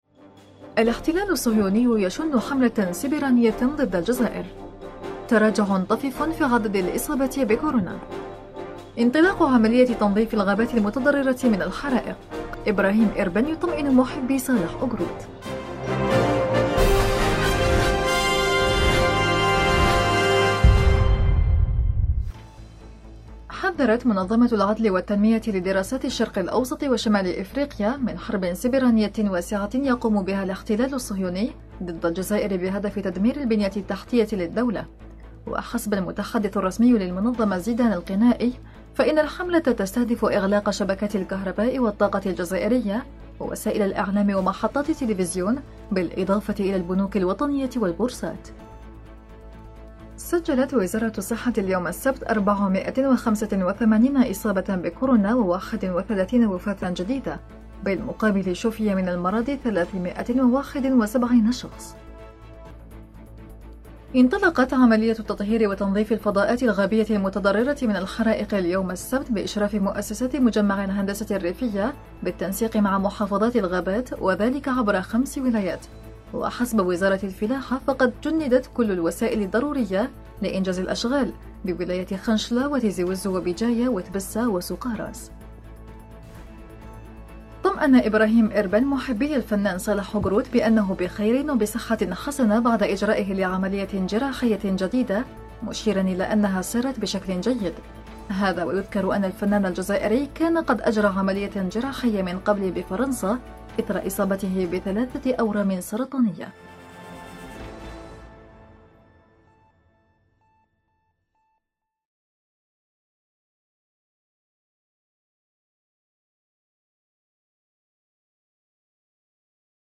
النشرة اليومية: الاحتلال الصهيوني يهاجم الجزائر – أوراس